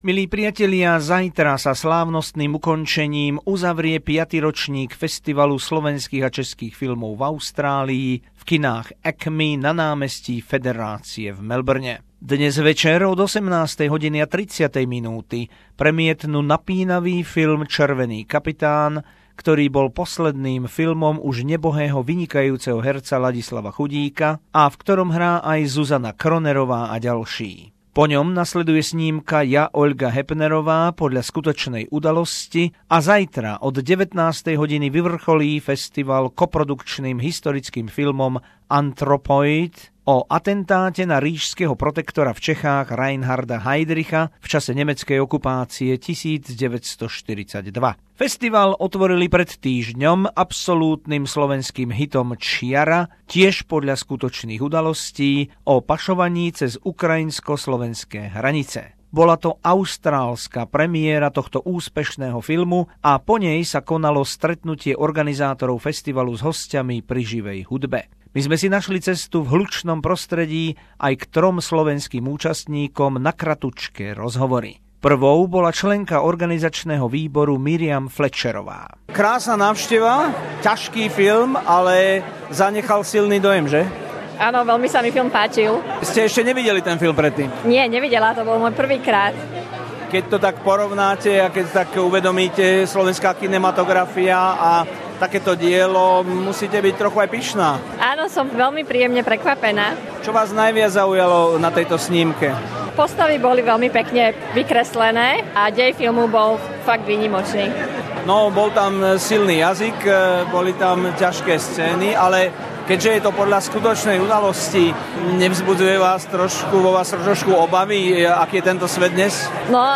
5th year of Czech and Slovak Film Festival in Australia a Big Success. Interviews with some guests after the opening.